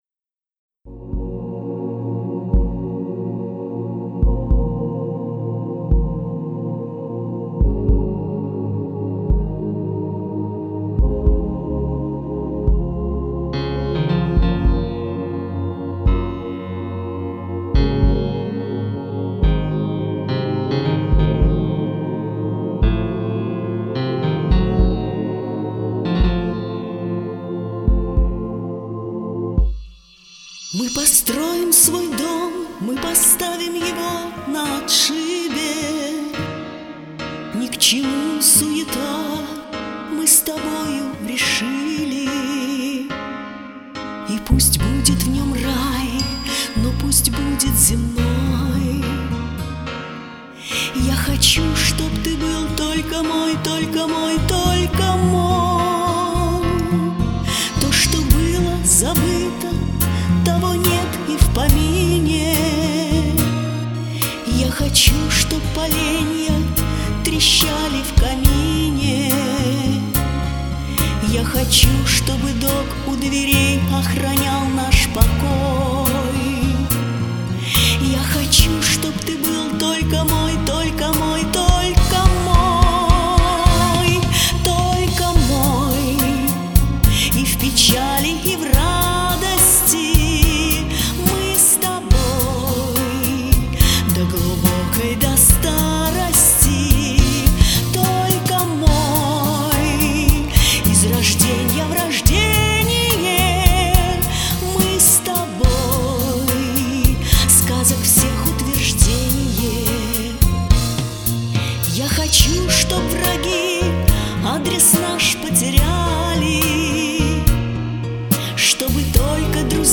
красиво, эмоционально!